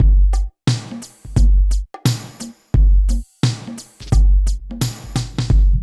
27 DRUM LP-L.wav